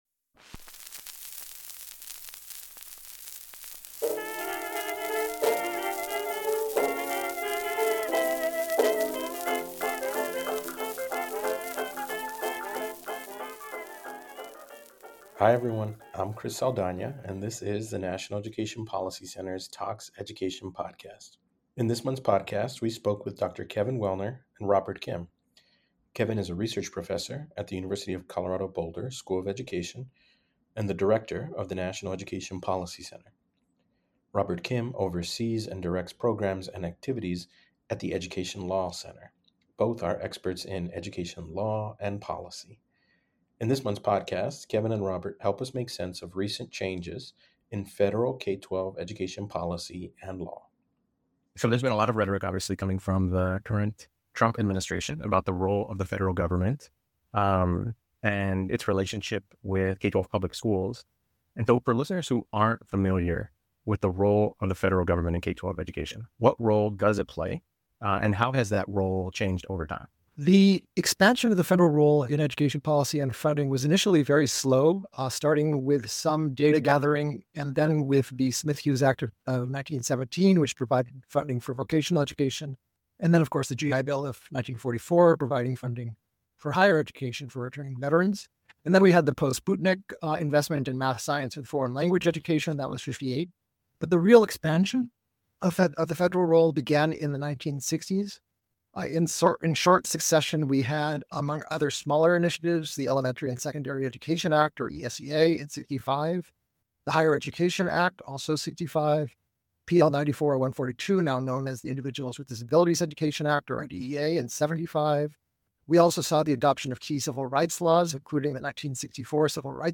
Education Interview